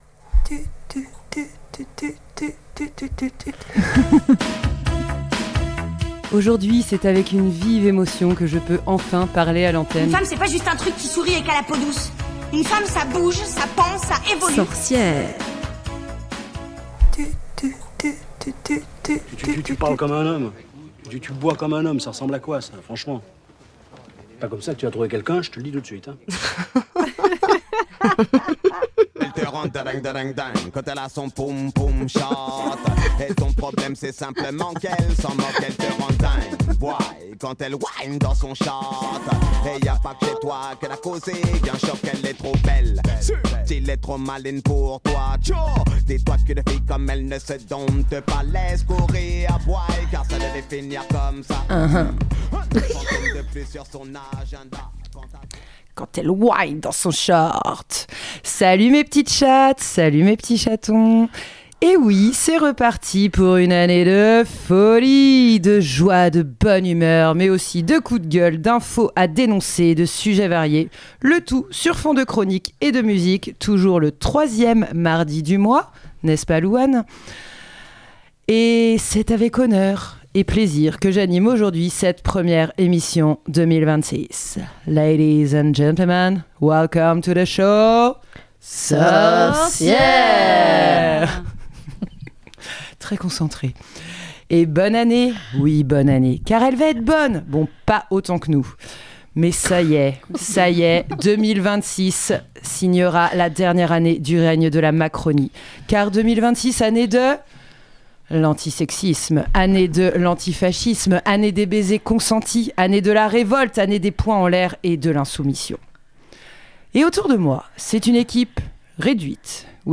Les sorcières vous souhaitent une bien bonne année !